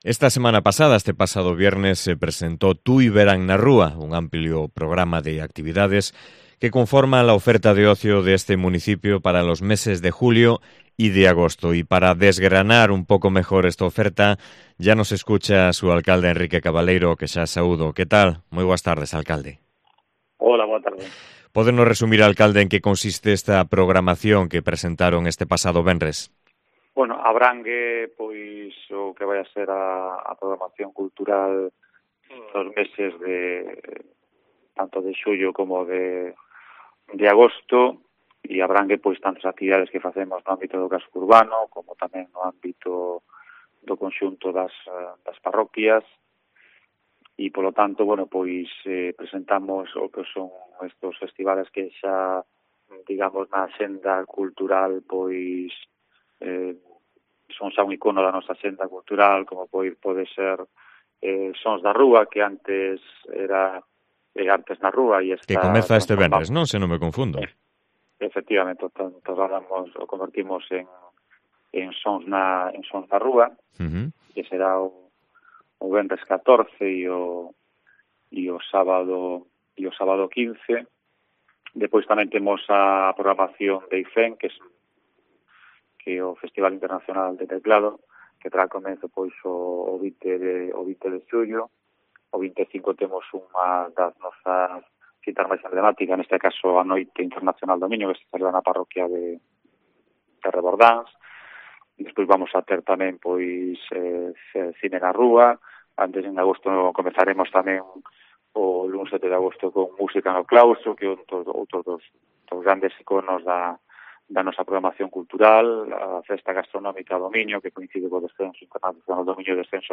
Entrevista con Enrique Cabaleiro, alcalde de Tui